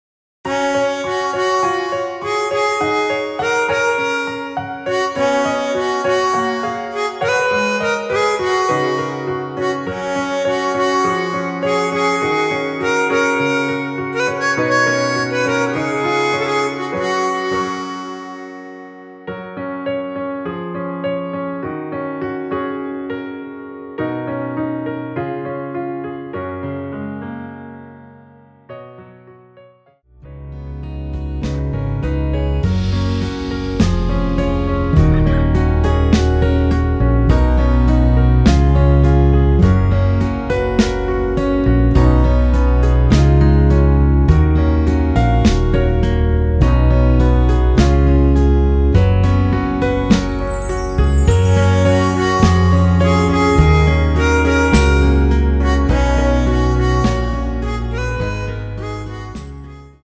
대부분의 남성분이 부르실수 있도록 제작한 키 입니다. 원키에서(-6)내린 MR입니다.
원곡의 보컬 목소리를 MR에 약하게 넣어서 제작한 MR이며